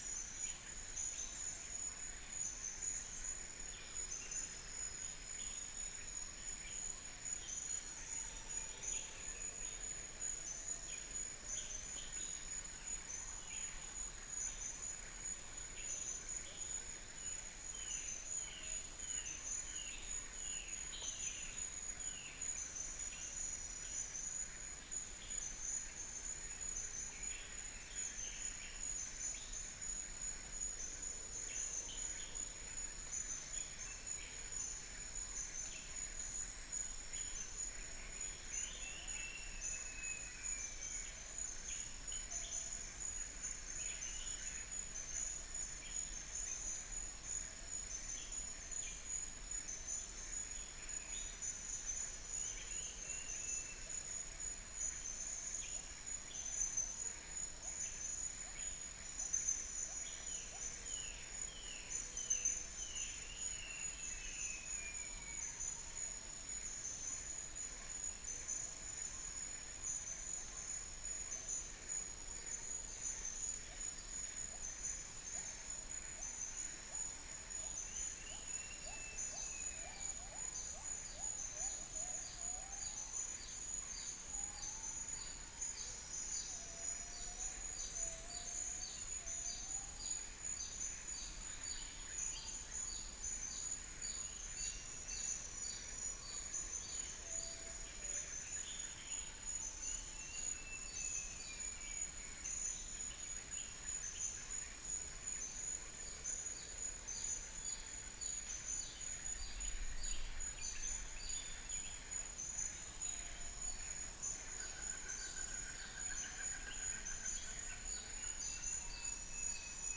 Cyanoderma erythropterum
Argusianus argus
Psilopogon duvaucelii
Trichastoma malaccense
Pycnonotus plumosus